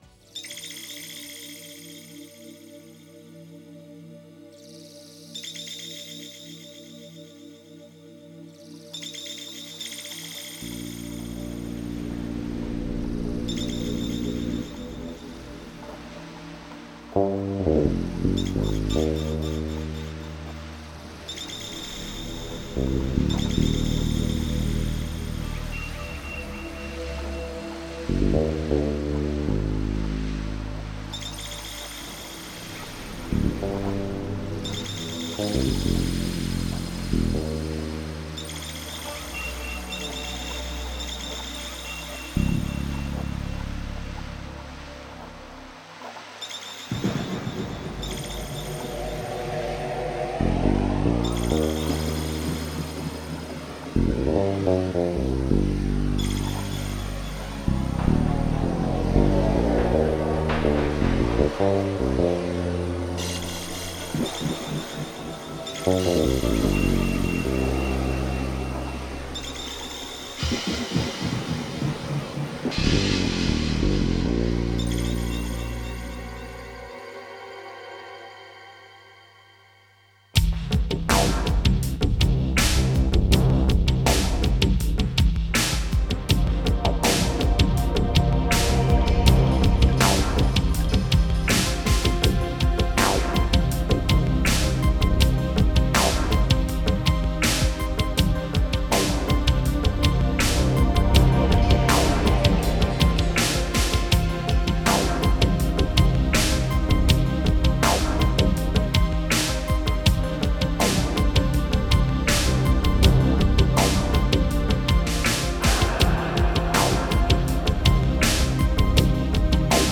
Genre: Enigmatic.